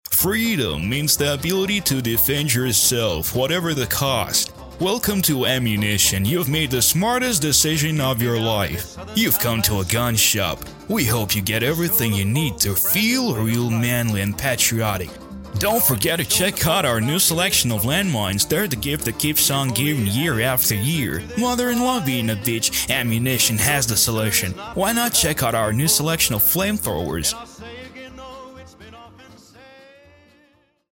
Commercial
Муж, Рекламный ролик/Зрелый
Спокойный, глубокий бас-баритон.
Студийное качество записи.